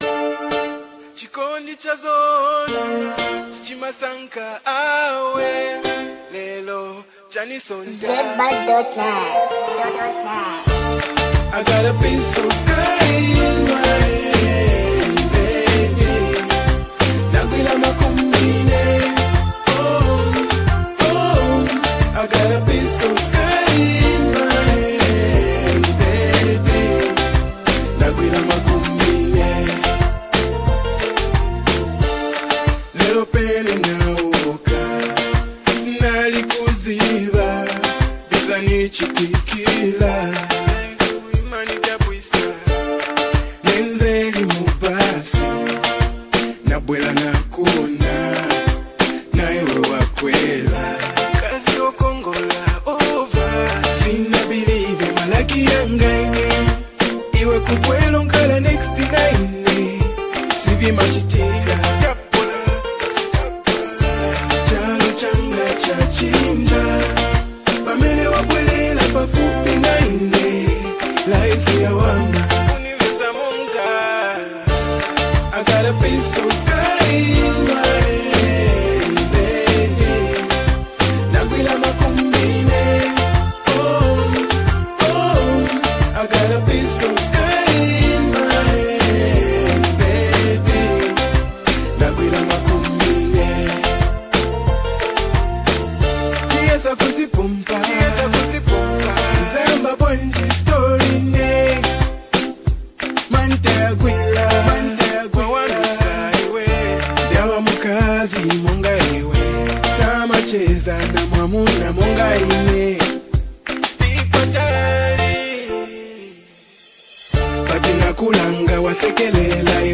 The song has a happy, carefree vibe that's hard to resist.